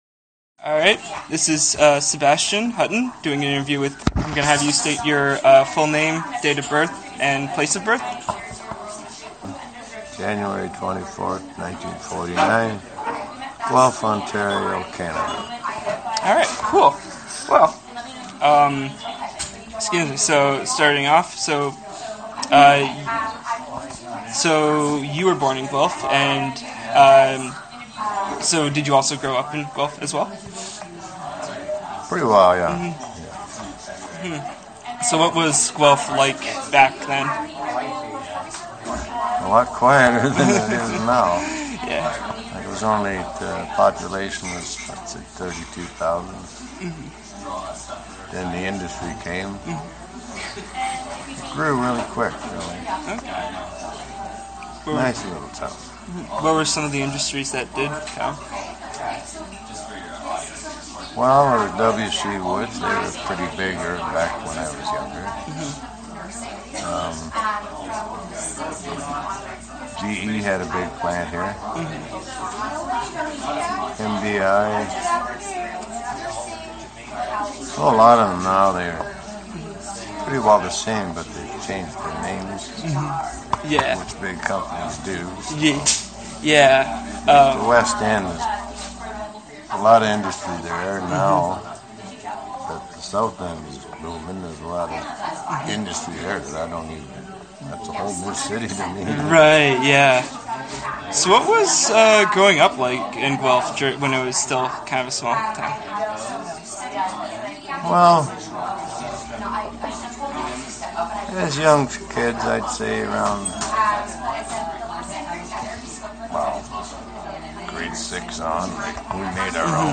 Participant B Interview The participant has requested to have his name taken off the record.